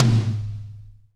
-DRY TOM 2-R.wav